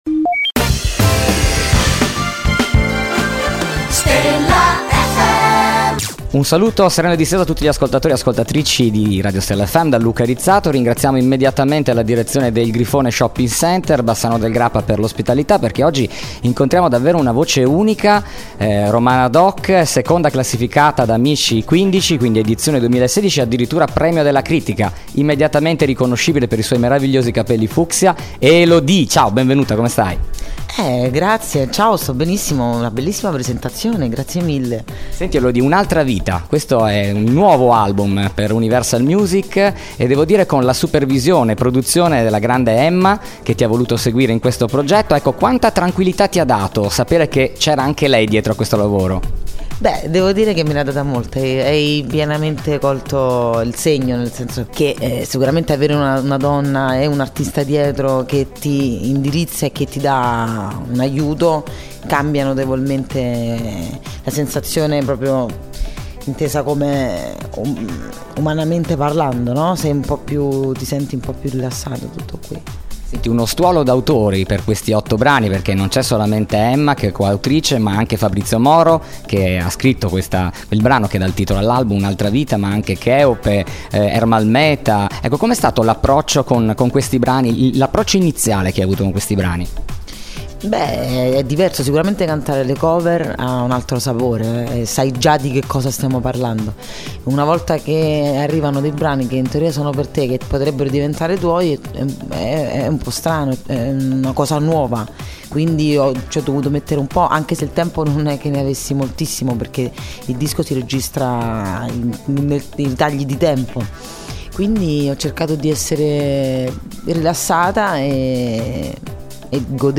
Intervista Elodie | Stella FM